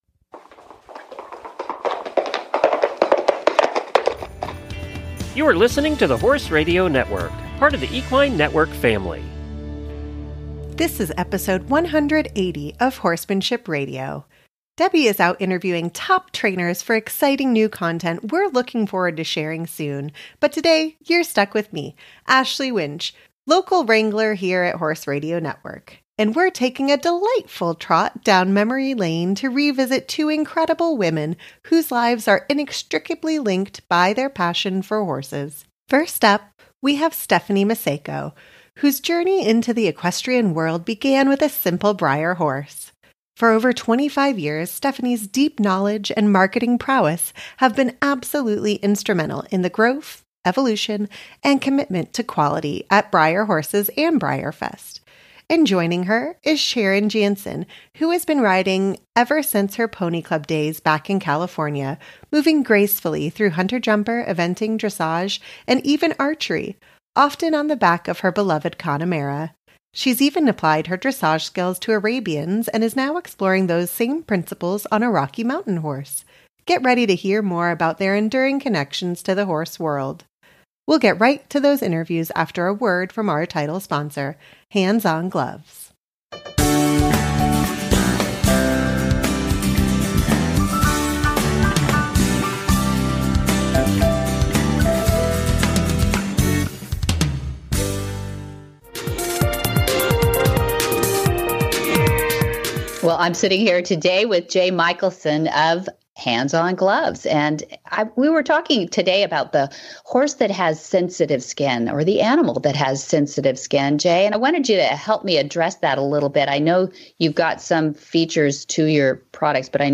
Join us as we explore the lifelong passion for horses with two remarkable women on this classic re-visit episode.